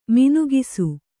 ♪ minugisu